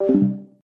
deafen-BWE6ozKl.mp3